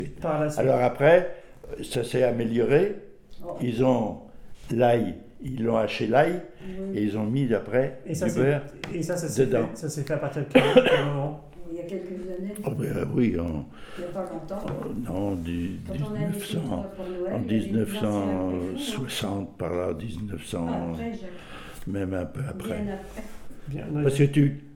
Enquête autour du préfou
Catégorie Témoignage